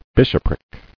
[bish·op·ric]